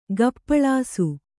♪ gappaḷāsu